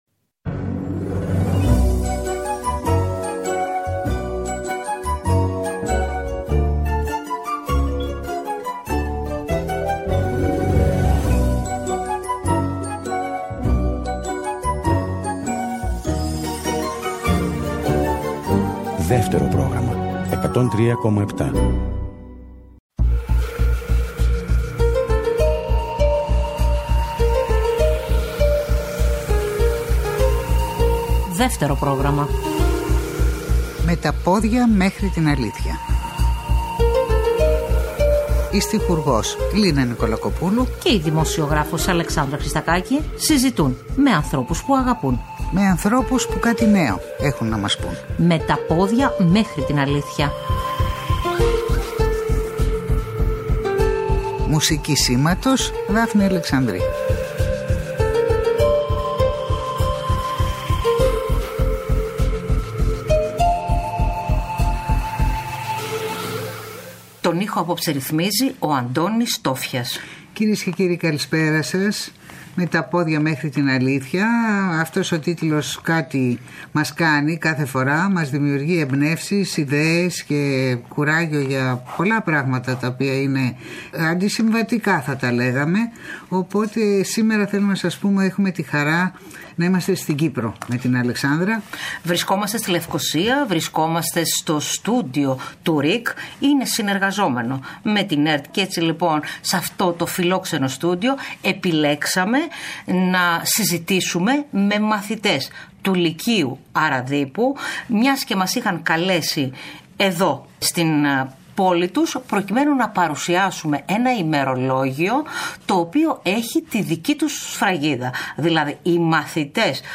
Η εκπομπή ” Με τα πόδια μέχρι την αλήθεια ” την Κυριακή 22 Γενάρη 2023 μεταδίδεται από στούντιο του ΡΙΚ στην Λευκωσία.